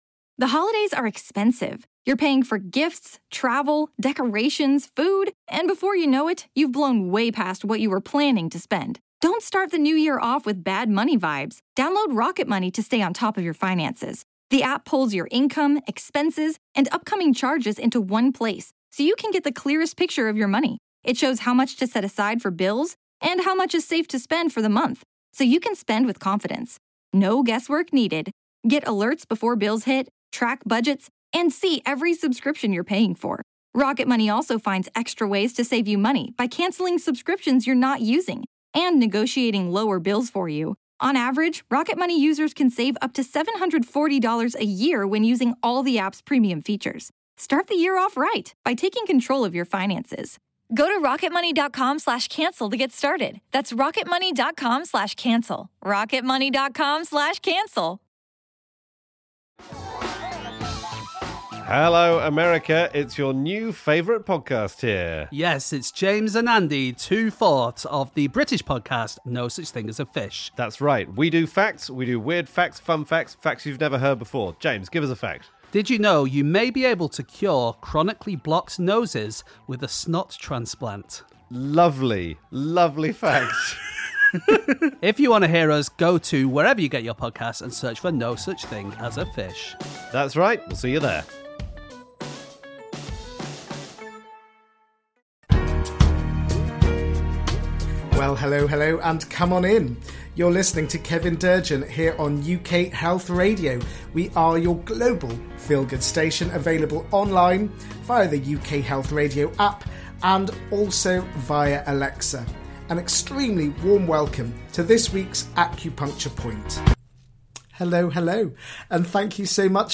As he is an acupuncturist there will be plenty of Chinese medicine related content. He will also play some gorgeous music to uplift your soul and get your feet tapping with happiness.